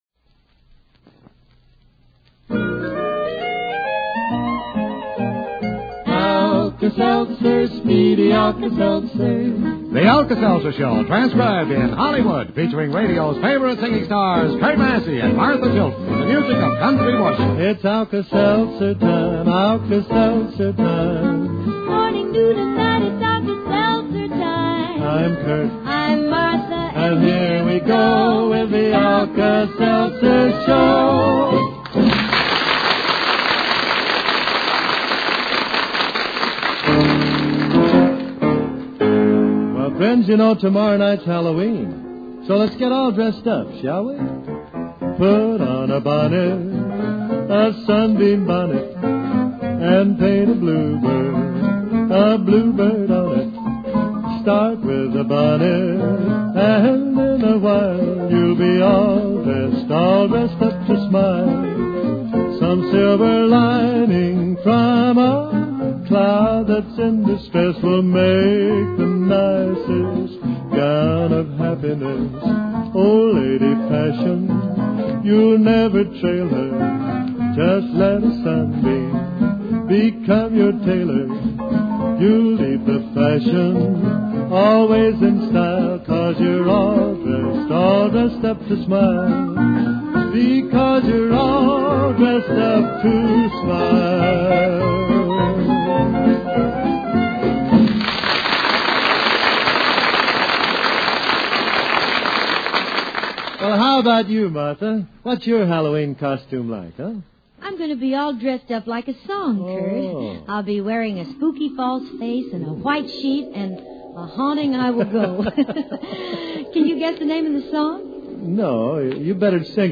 At this time, the show was on Mutual at noon, CBS at 5:45 P. M. The first tune is "Put On A Bonnet." The system cue is added live.